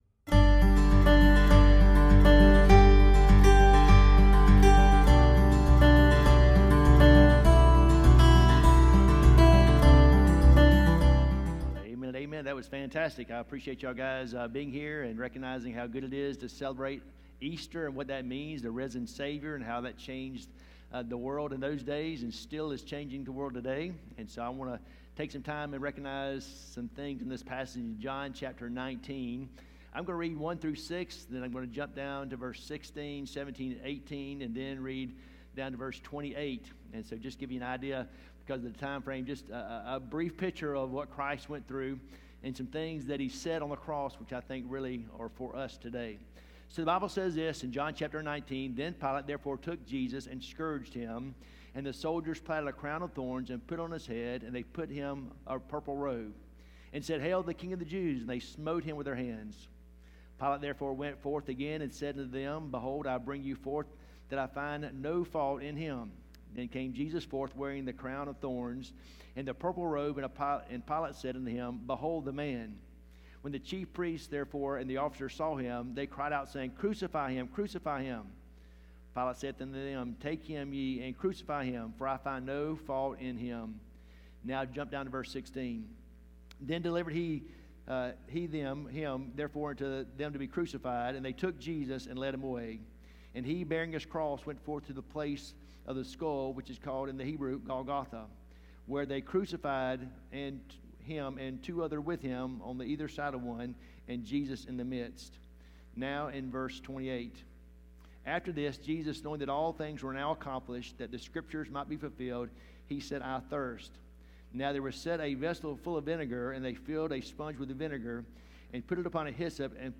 Sermons 2 – Sardis Baptist Church | Worthington Springs, FL